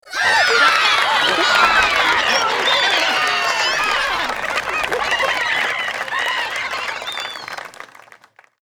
Golf_Crowd_Applause.ogg